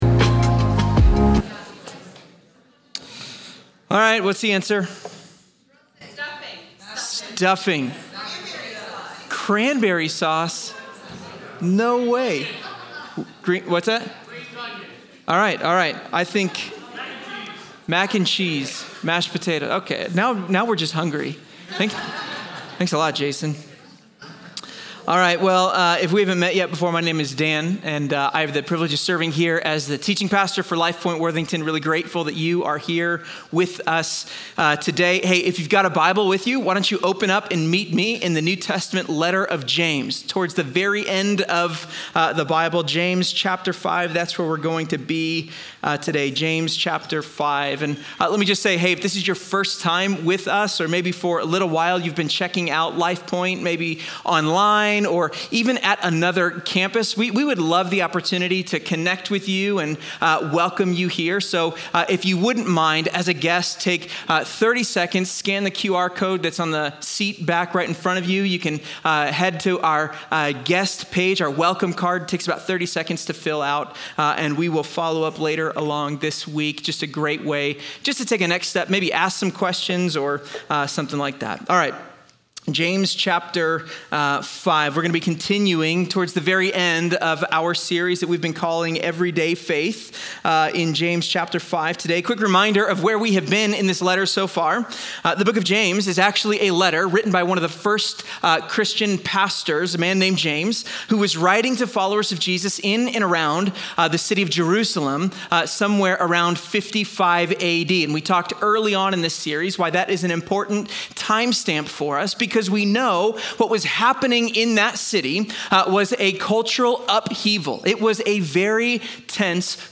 Join us as we explore how James 5:7–11 calls believers to cultivate patience as a form of steadfast allegiance to God amid trials and waiting. The sermon examines the tension between our impulse for instant results and the biblical mandate to wait—not passively, but with a hopeful, disciplined trust rooted in God’s promise.